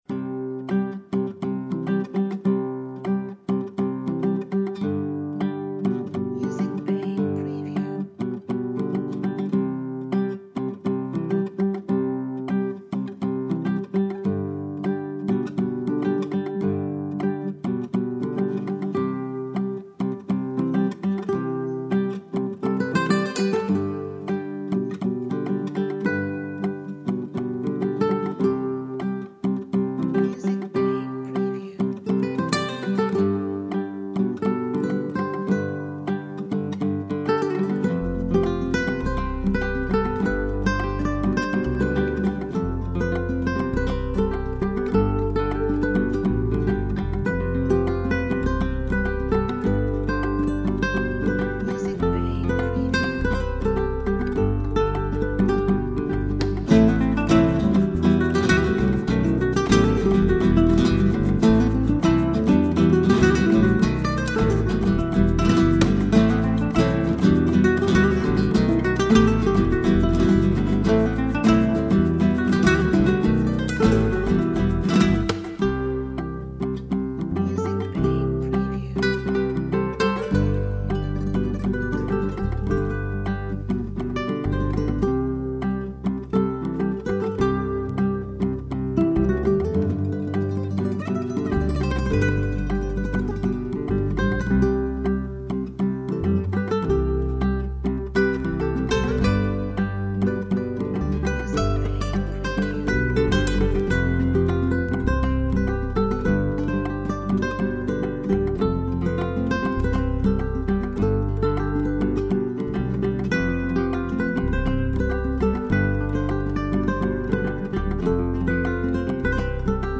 Acoustic guitar music for slideshow.
Tempo (BPM): 112